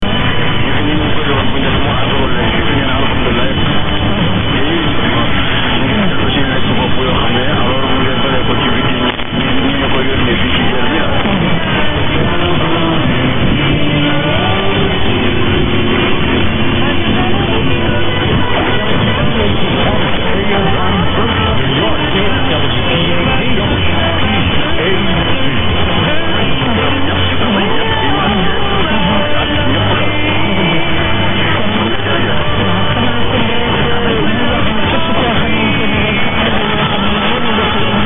I had a fair signal from 930 WPAT Paterson, NJ this morning at 0300. (Fair for a dx signal..)
There were no other unusual stations noted at this time that I can hear on my recording.